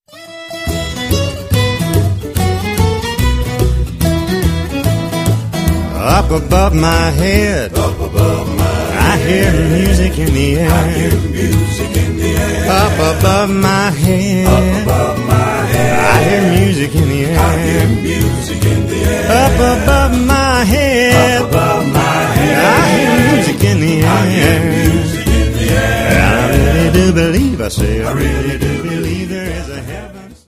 Songs od Faith, Worship and Praise
rein akkustisch
• Sachgebiet: Country